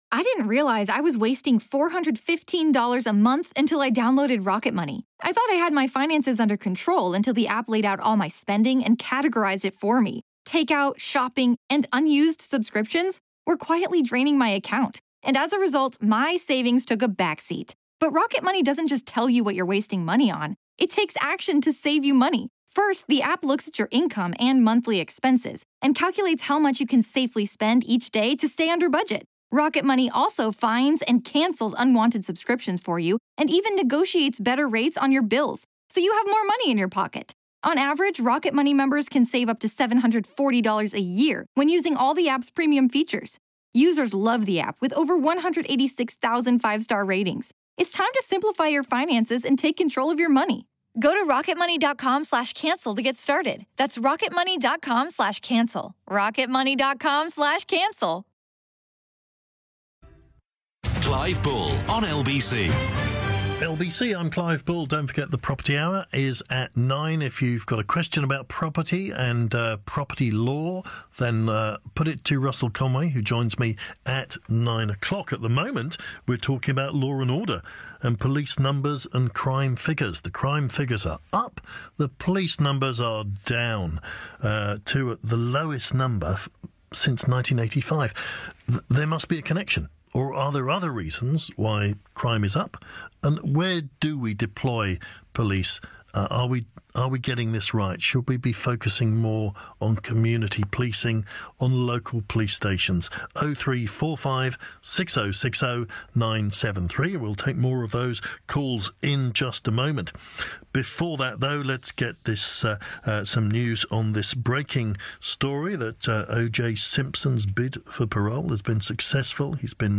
breaking news report aired on LBC